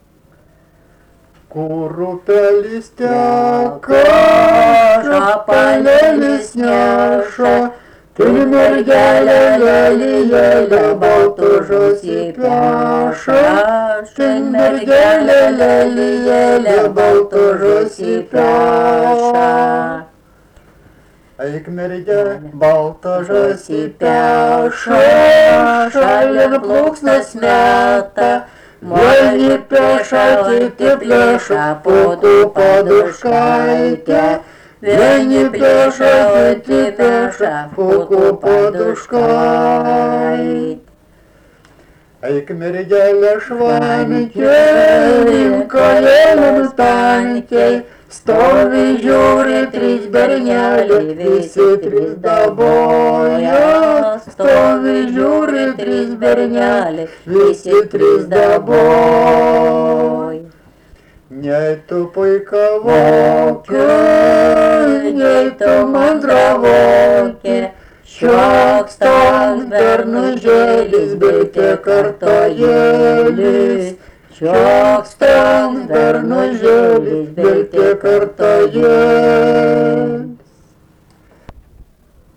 Dalykas, tema daina
Erdvinė aprėptis Obeliai
Atlikimo pubūdis vokalinis